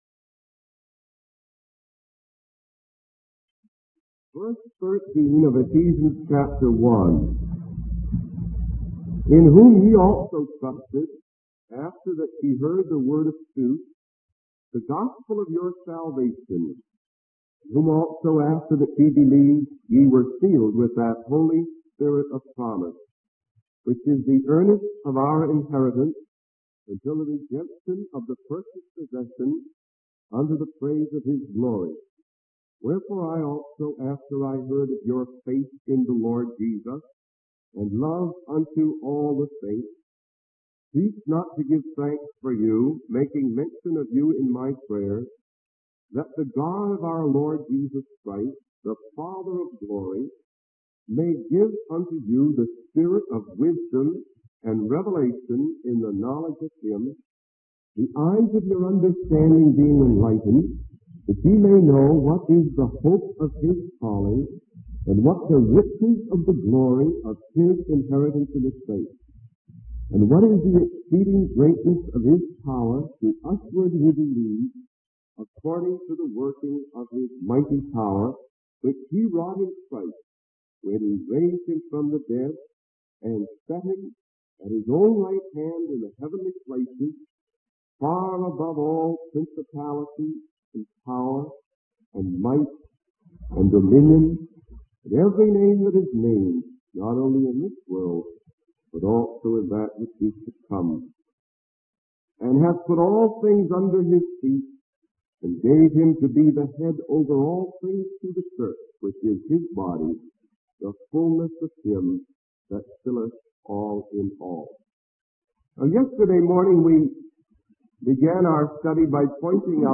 In this sermon, the preacher discusses the concept of being children of God and how it relates to the Old Testament.